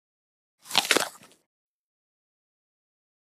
DINING - KITCHENS & EATING APPLE: INT: Single throaty bite into an apple.